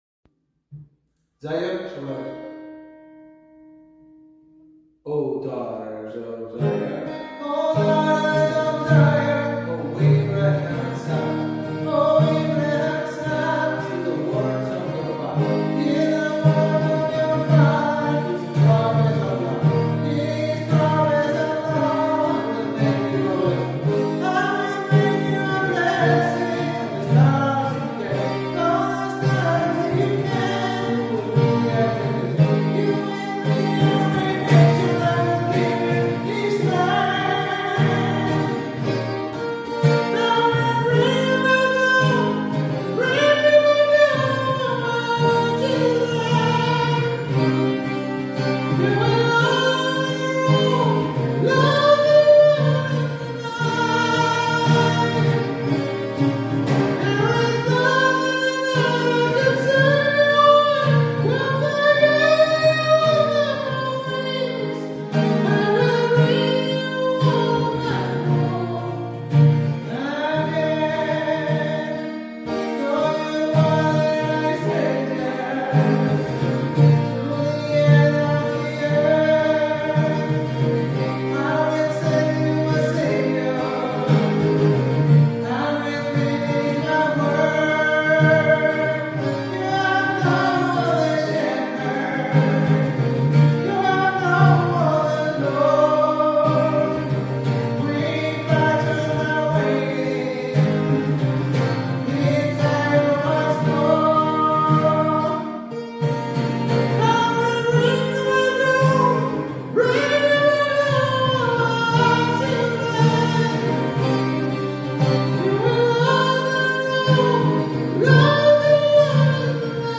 zion-sopranos.mp3